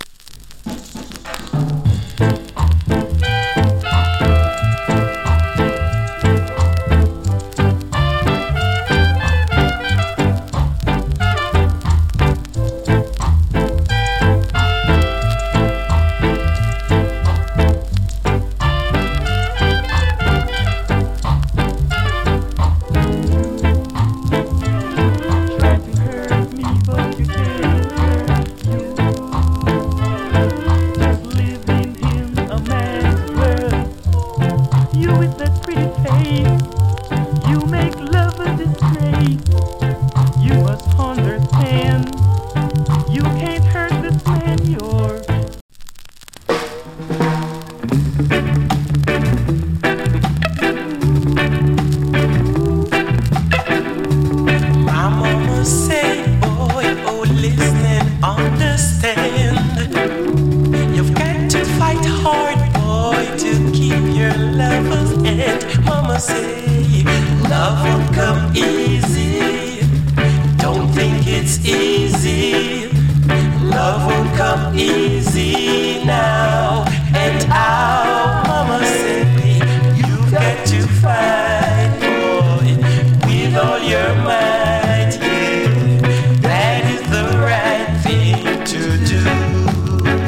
プレスによるチリ、パチノイズ A-SIDE 有り。